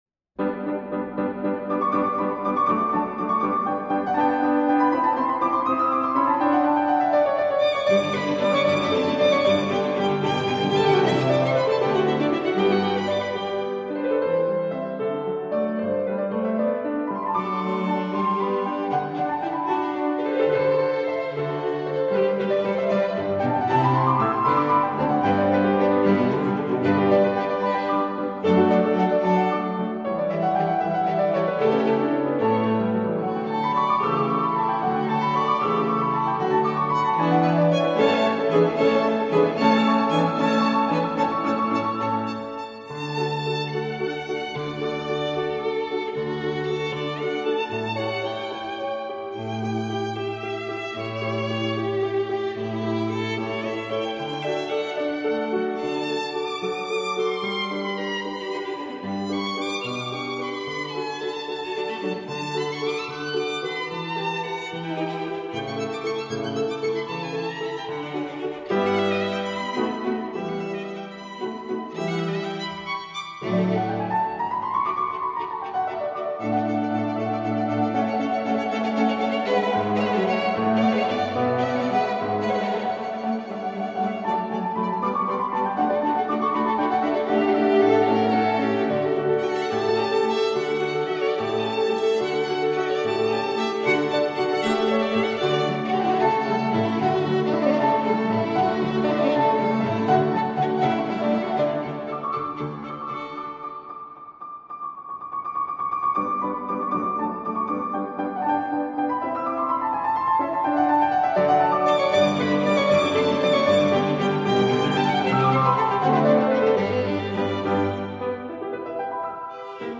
Soundbite 3rd Movt